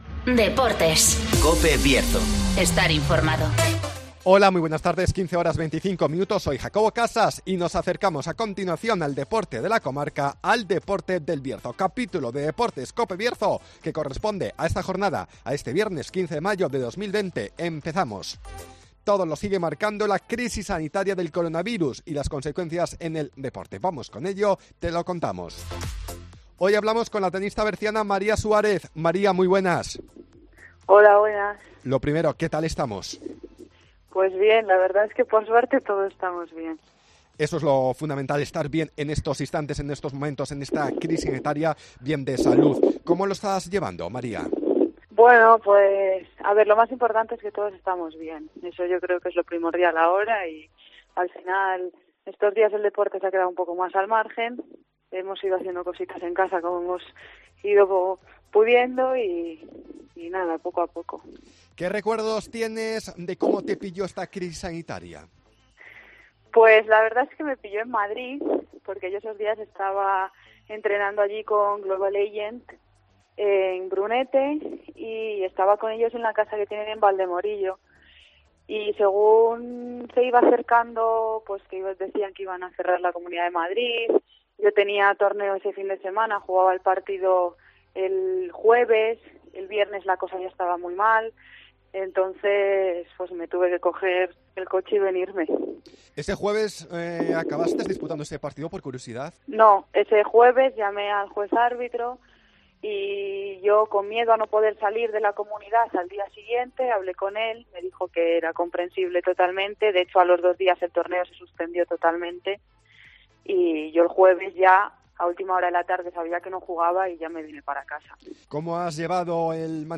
-Entrevista